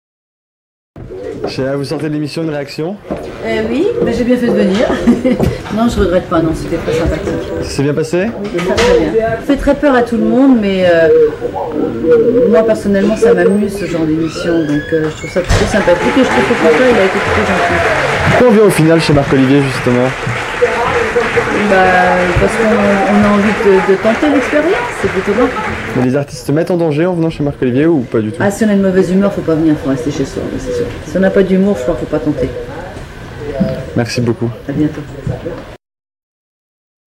La sortie en coulisses (inédit à la télé) =